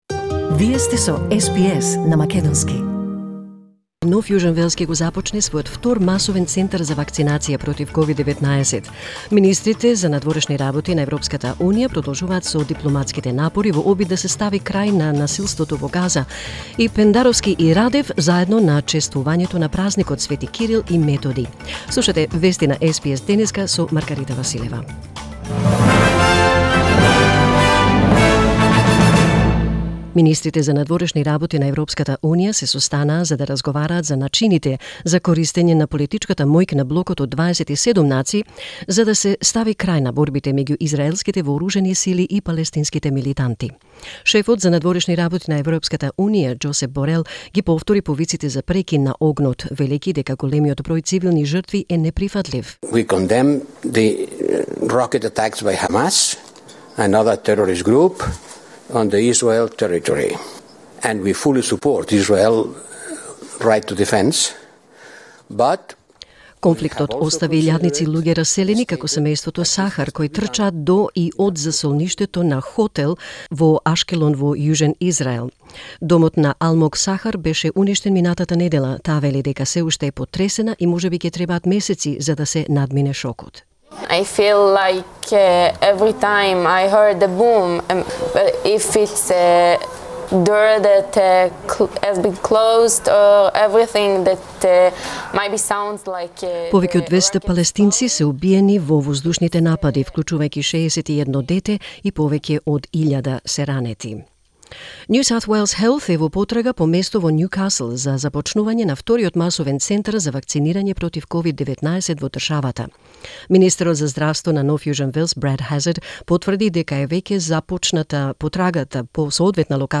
SBS News in Macedonian 19 May 2021